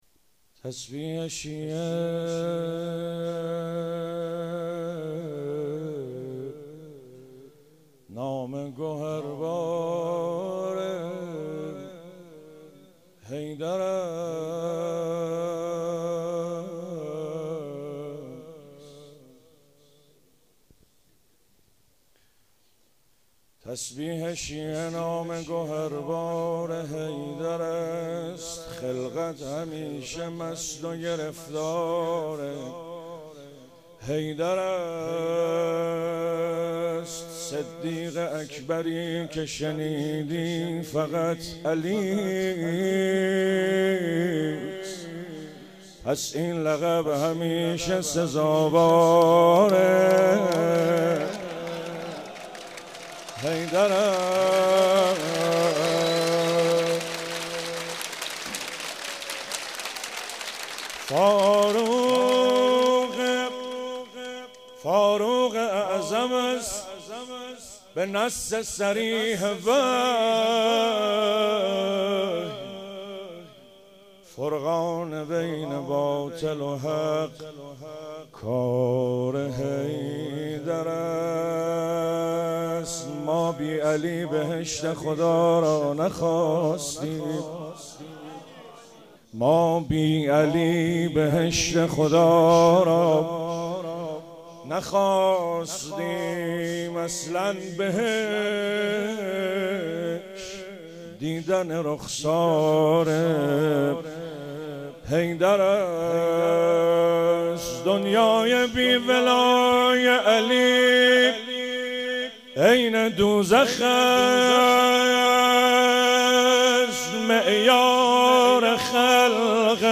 جشن میلاد پیامبراکرم(ص) و امام جعفرصادق(ع) 98